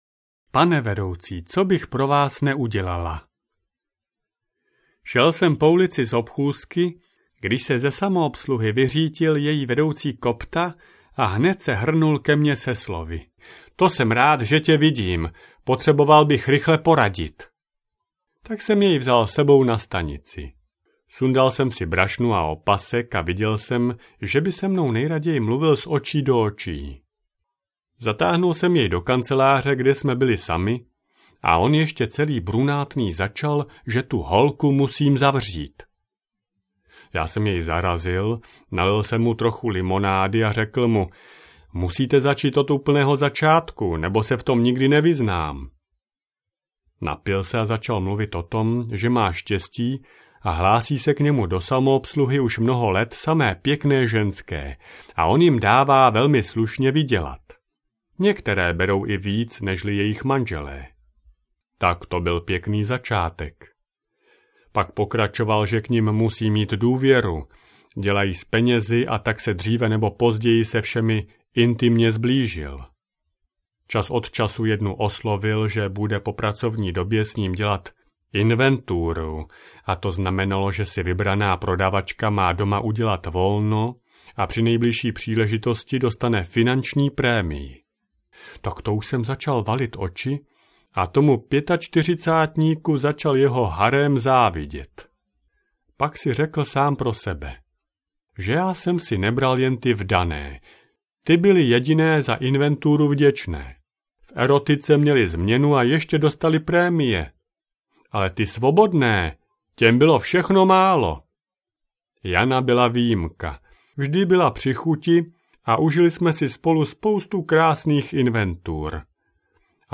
Krimi dekameron 2 audiokniha
Ukázka z knihy